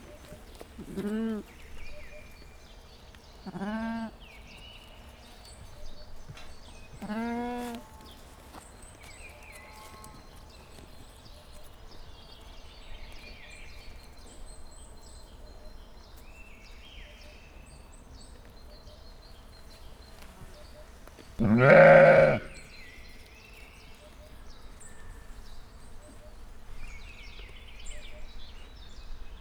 Directory Listing of /_MP3/allathangok/felsolajoszoo2015_professzionalis/juh/
haromszorfeketebaranyok_egyszerfelnottjuh_cardioid00.29.WAV